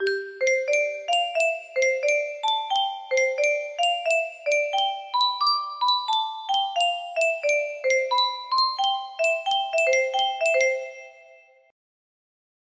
A music box cover